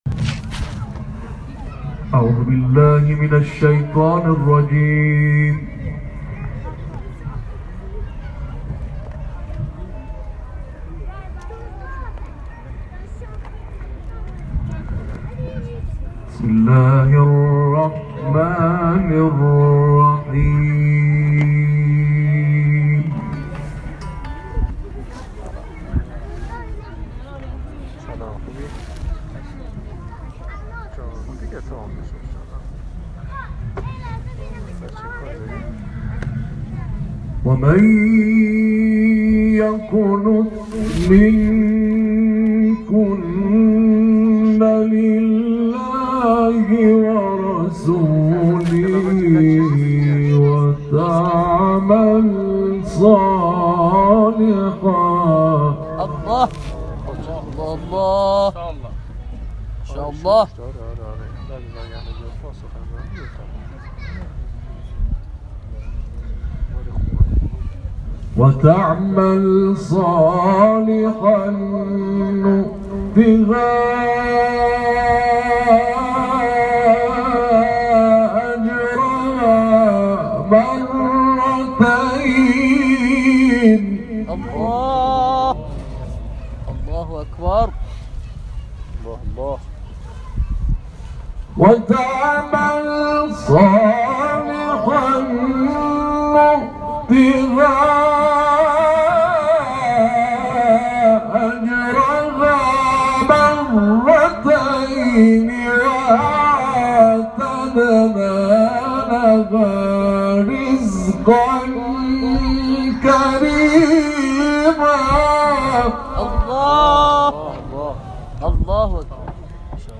محفل انس با قرآن کریم به مناسبت عید غدیر خم
در مسجد حضرت مولای متقیان(ع) برگزار شد.